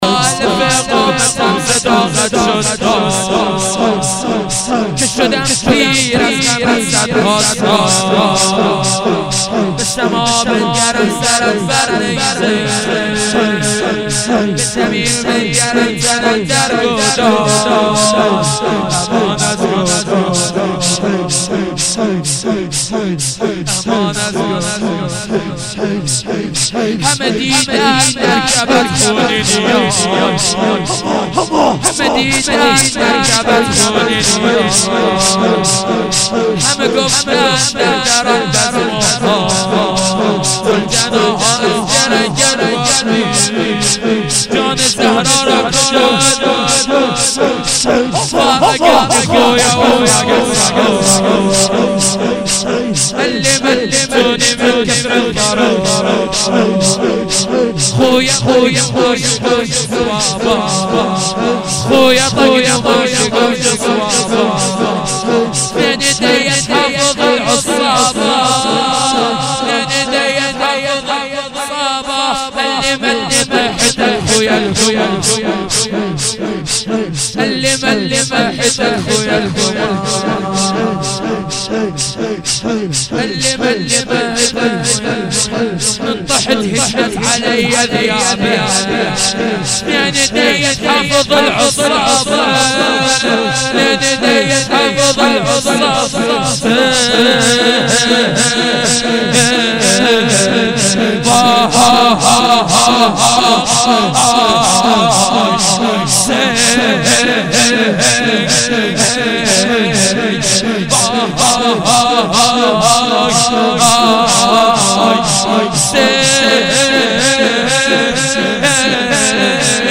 shahadat-emam-bagher-93-shor-farsi-arabi.mp3